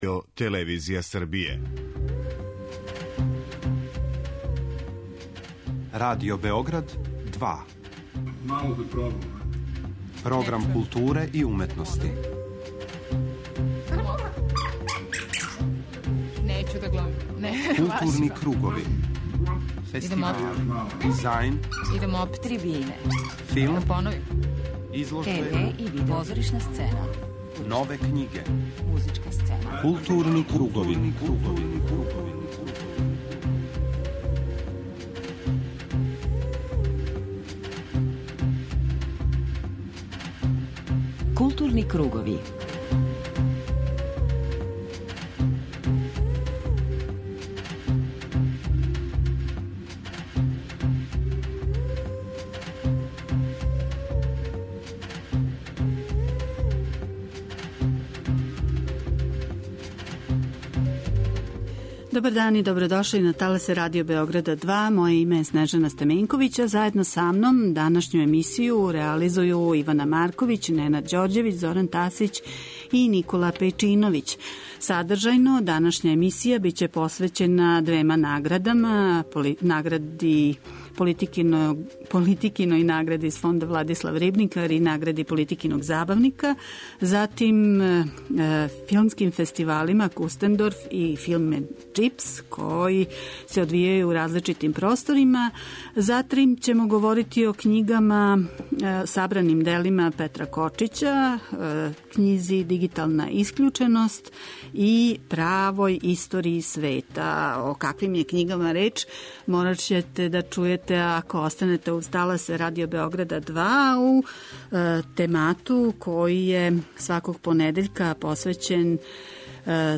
преузми : 41.17 MB Културни кругови Autor: Група аутора Централна културно-уметничка емисија Радио Београда 2.